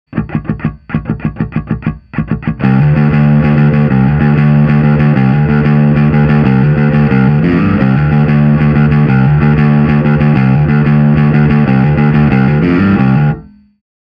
An extremely versatile, warm sounding transparent overdrive.
Smog on BASS
Amp: Ampeg SVT-CL
Cab: Ampeg SVT-810E
Bass: Fender Jazz ‘66